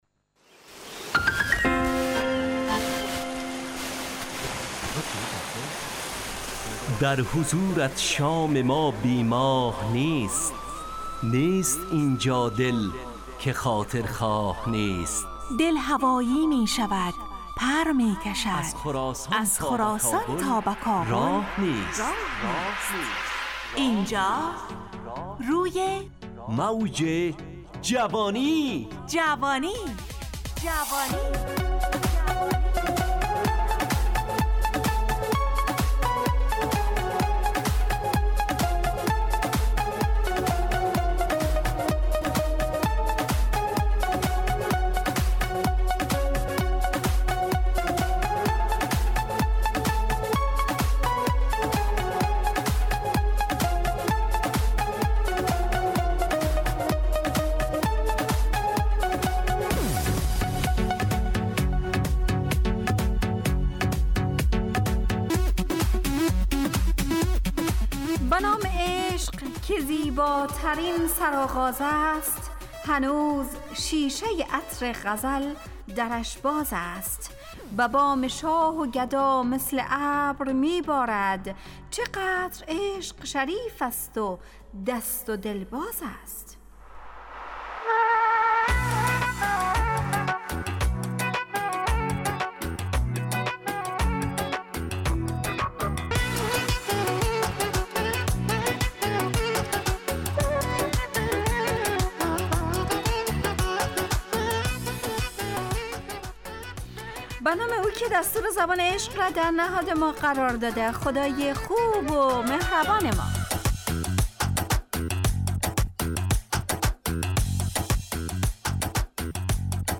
روی موج جوانی، برنامه شادو عصرانه رادیودری.
همراه با ترانه و موسیقی مدت برنامه 55 دقیقه . بحث محوری این هفته (دنیا) تهیه کننده